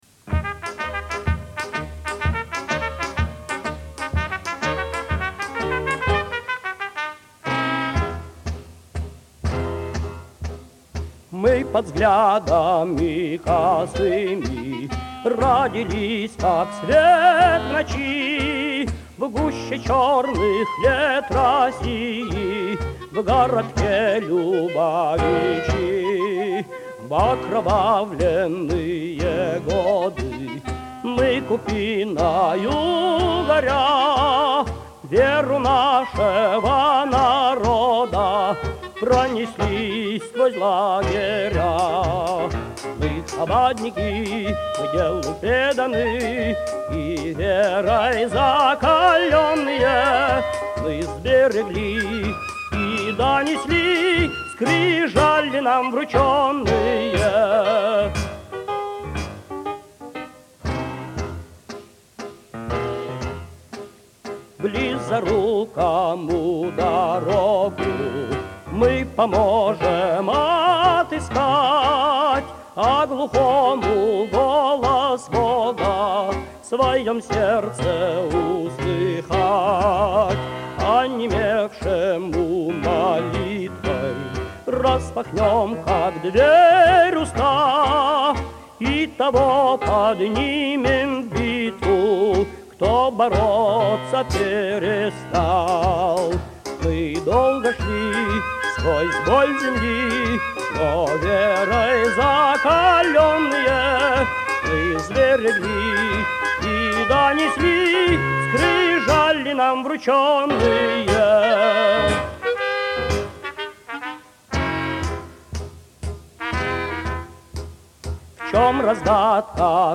מקורו של הלחן, הוא לחן רוסי עממי.
המנון_חבד_ברוסית.mp3